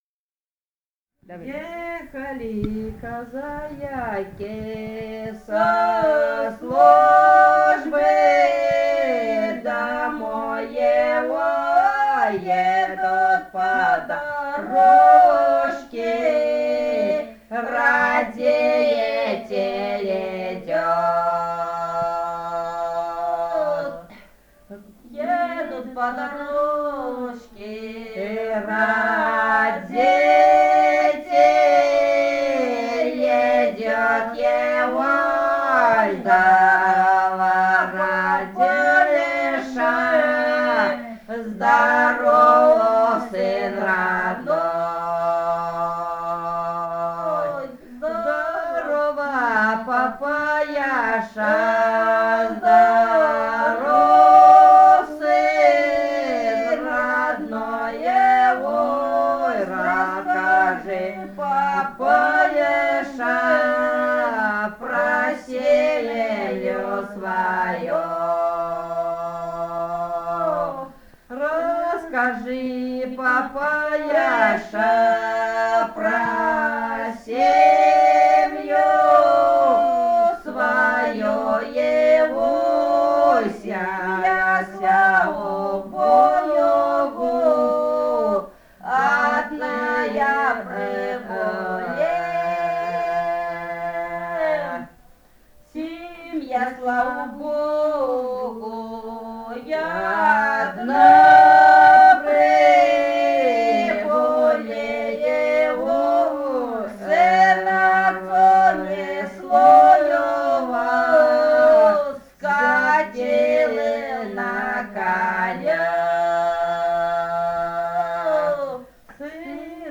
полевые материалы
Бурятия, с. Петропавловка Джидинского района, 1966 г. И0903-17